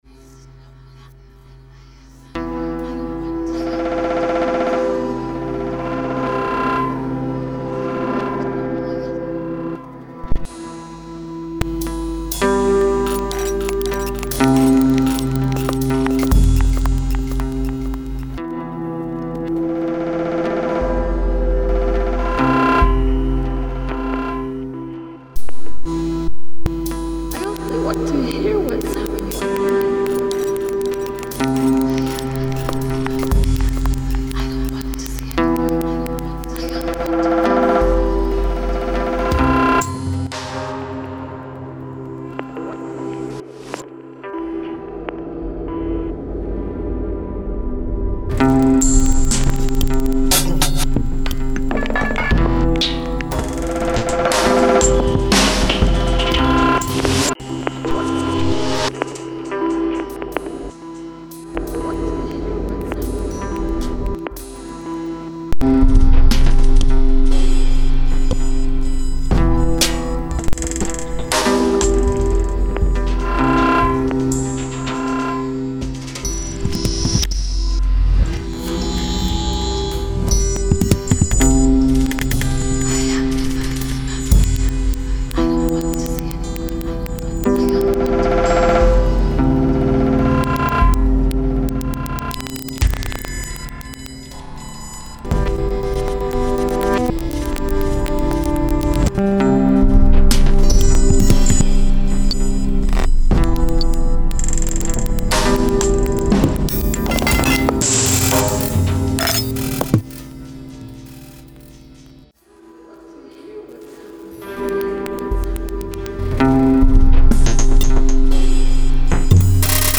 Musique plus organique cependant, moins électronique, quoi !